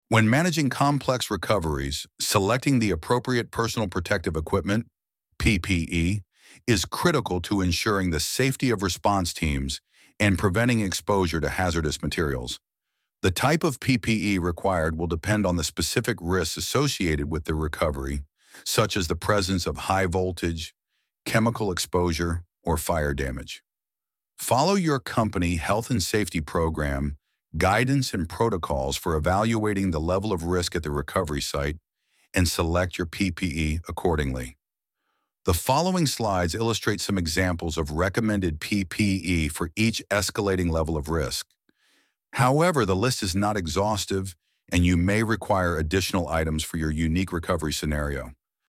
ElevenLabs_Topic_1.3.5.1.mp3